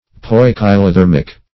Poikilothermic \Poi`ki*lo*ther"mic\ (-th[~e]r"m[i^]k), a. [Gr.